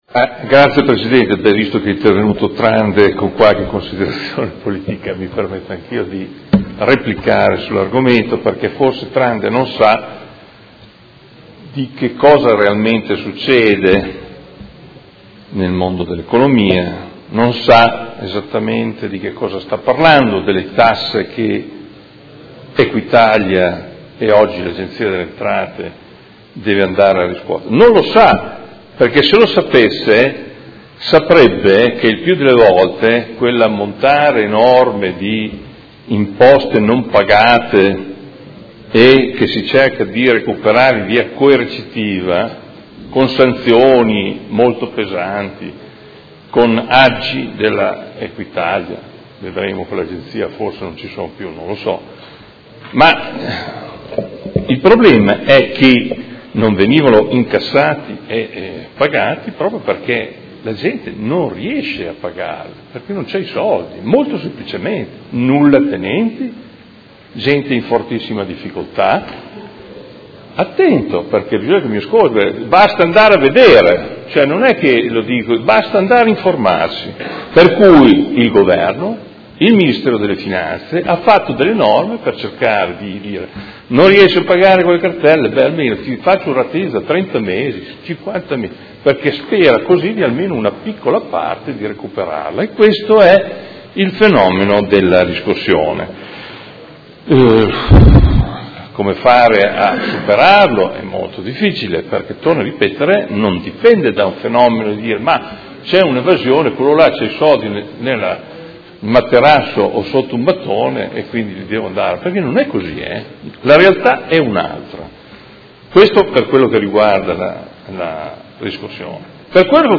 Adolfo Morandi — Sito Audio Consiglio Comunale
Seduta del 30/03/2017. Dichiarazione di voto su proposta di deliberazione: Affidamento della riscossione coattiva delle entrate comunali, tributarie e patrimoniali, all’Ente nazionale della riscossione, Agenzia delle Entrate